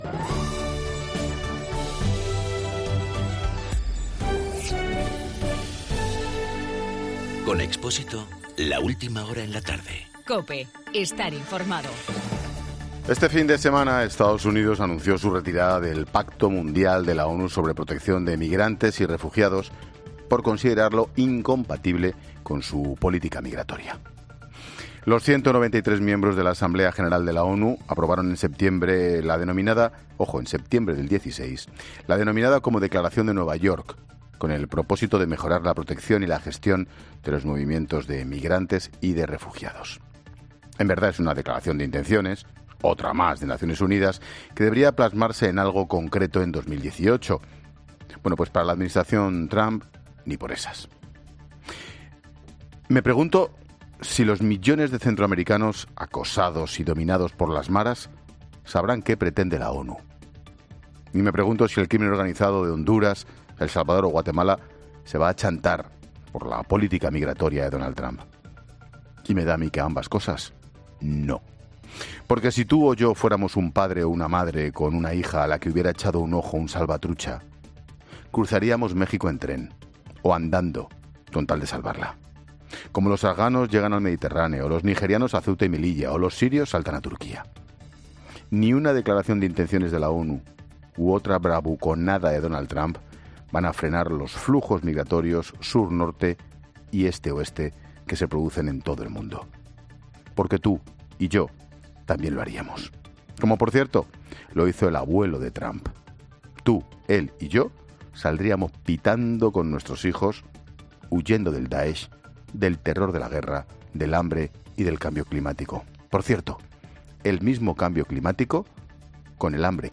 Monólogo de Expósito
Ángel Expósito analiza en su monólogo de las 18 horas la política migratoria de Donald Trump en EEUU.